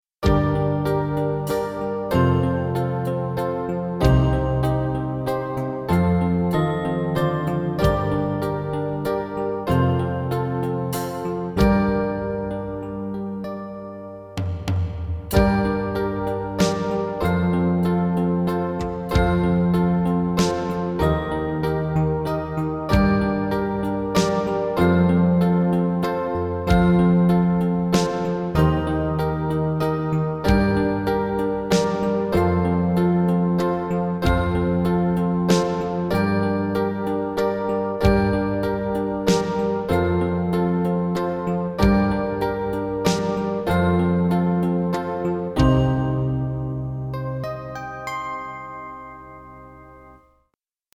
MT-32 MIDI conversion
As recorded from the original Roland MT-32 score